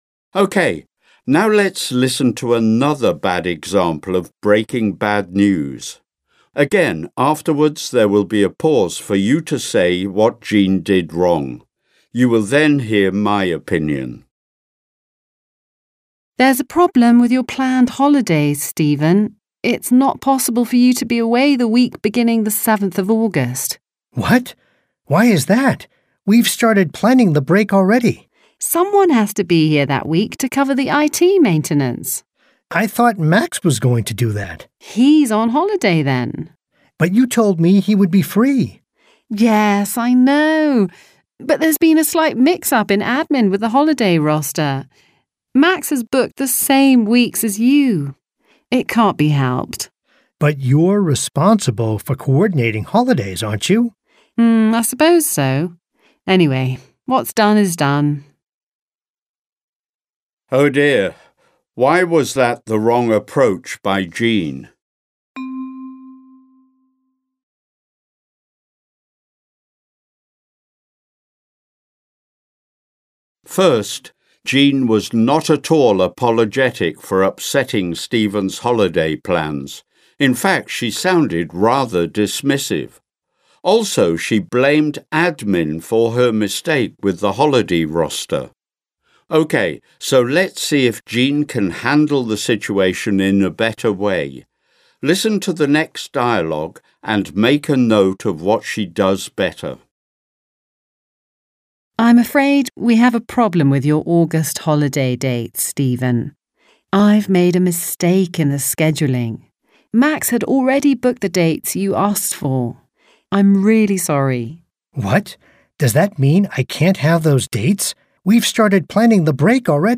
Easy English - Exercise: Dialogues (II) | ZSD Content Backend
Audio-Übung